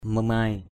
/mə-maɪ/ (Kh. mémai) mamai m=m [A, 379] [Bkt.]